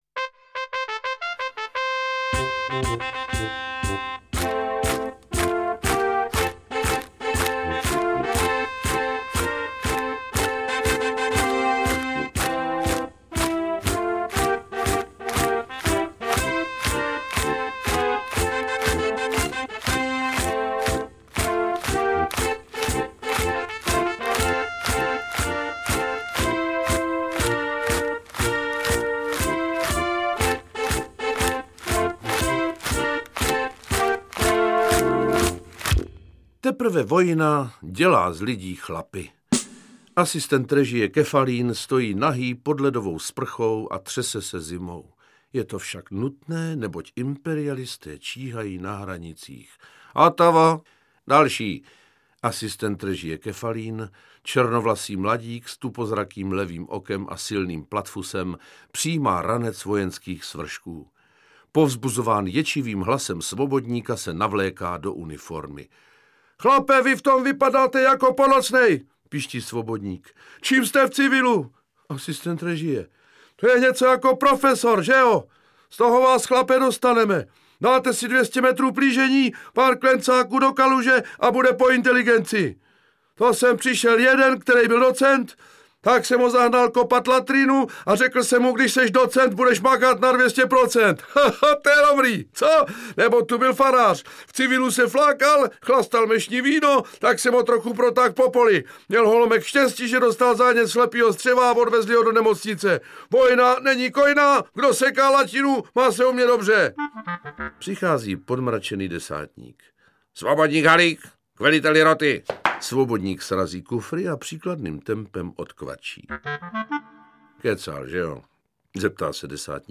Interpret:  Miroslav Donutil